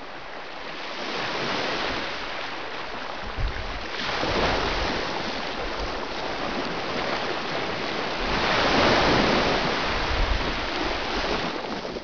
Wasser_Meer
Stimmungsbild Cala Millor, Mallorca
Meerrauschen.wav